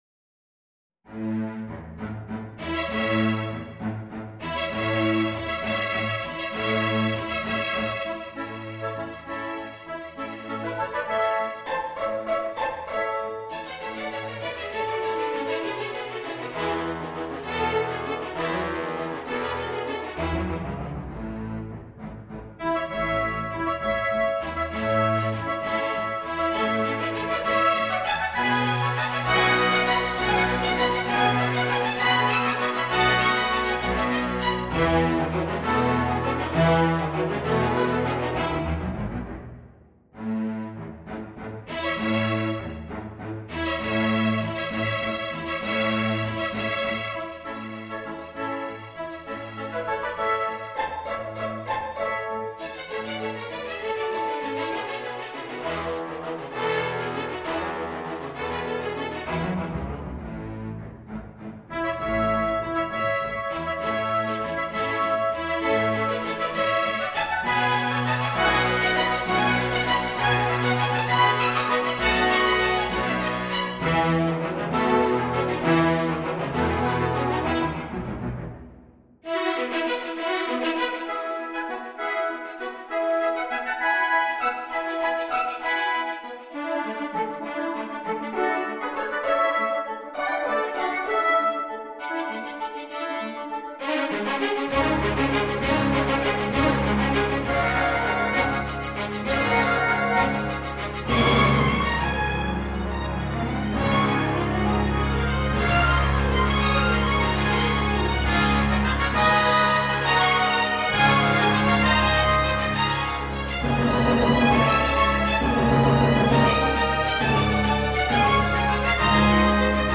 Orchestration
2 Flutes, 2 Oboes, 2 Clarinets in Bb or A, 1 Bassoon
Strings (Violin 1, Violin 2, Viola, Cello, Bass)